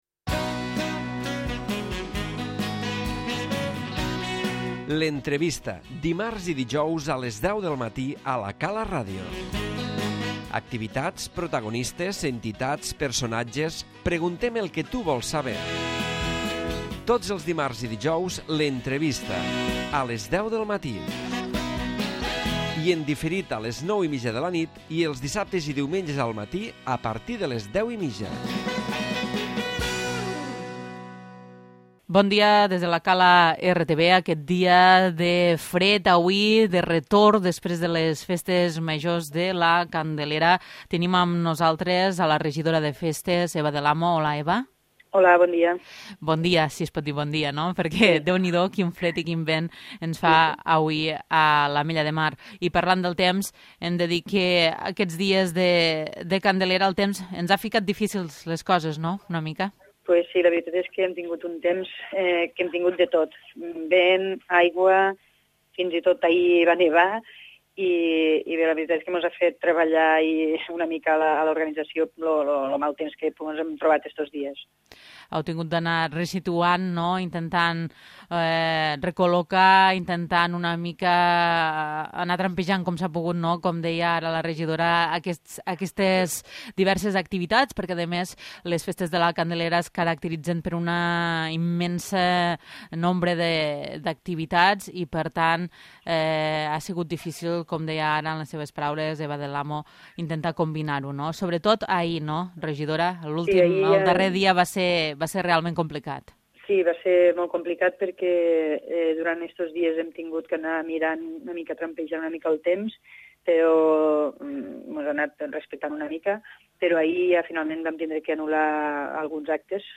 L'Entrevista
Aquest dijous hem fet balanç de la Candelera 2015 amb la Regidora Festes, de l'Ajuntament de l'Ametlla de Mar, Eva del Amo.